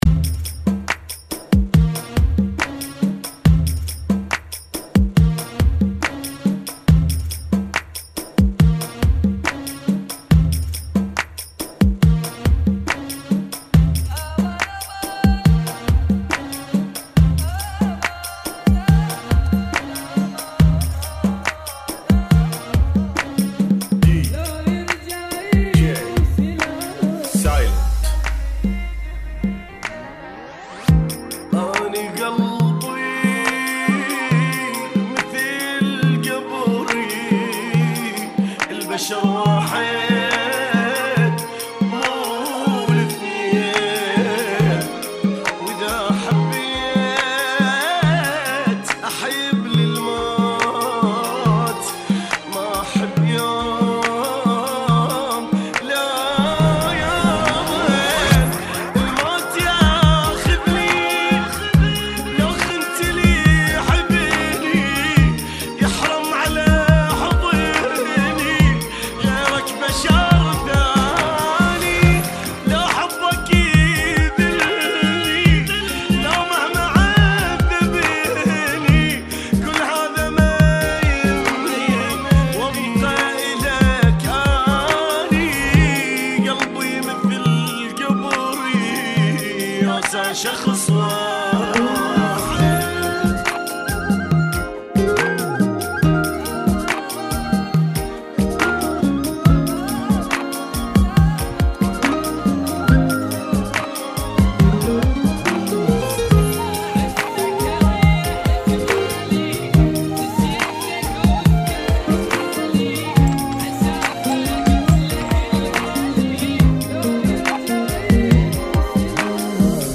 [ 70 BPM ]